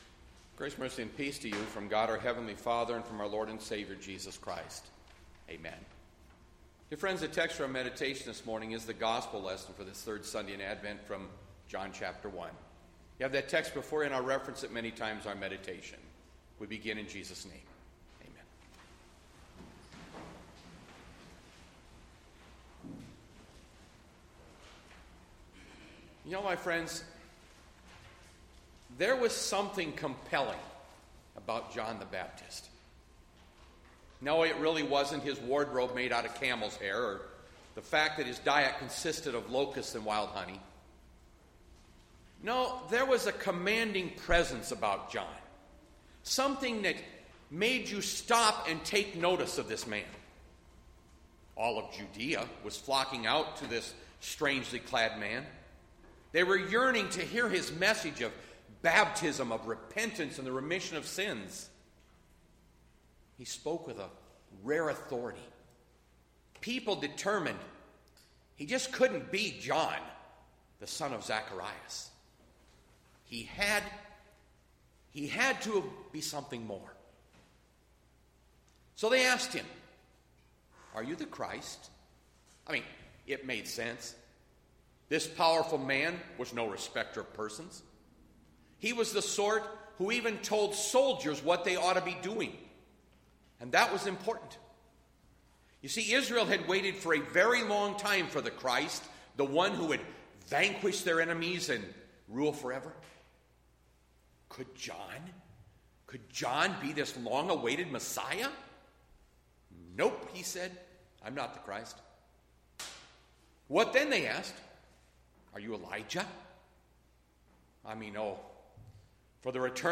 Bethlehem Lutheran Church, Mason City, Iowa - Sermon Archive Dec 13, 2020